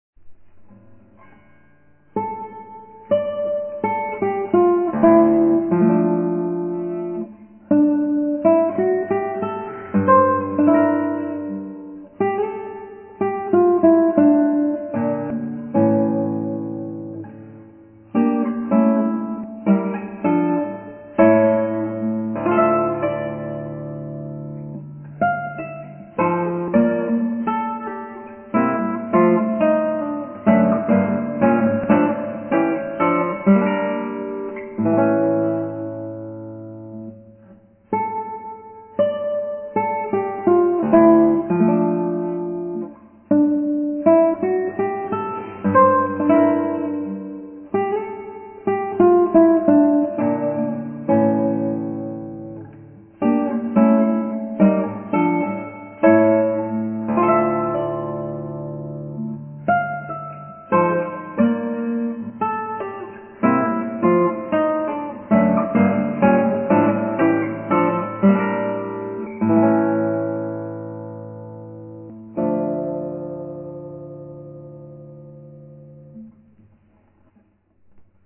エンデチャ、尾野ギターで